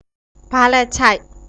Labial
Ba-lei-cheik {ba.lèý-hkyaing.}